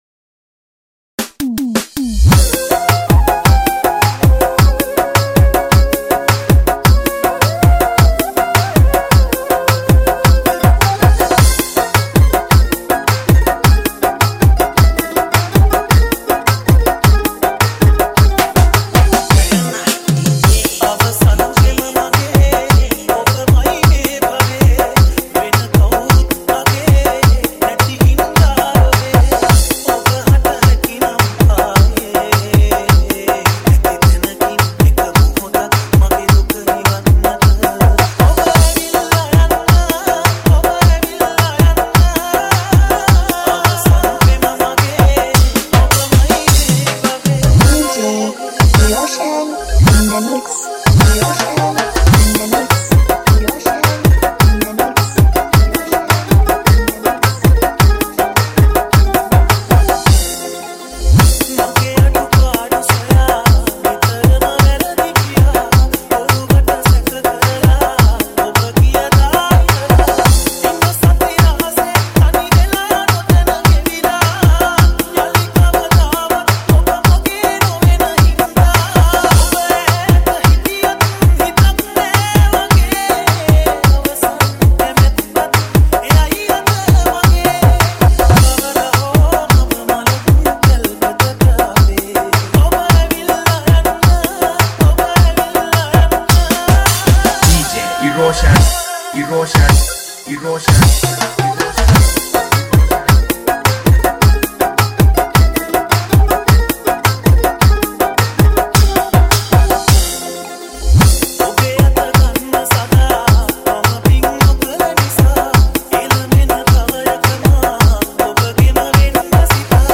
Live Band Style Mix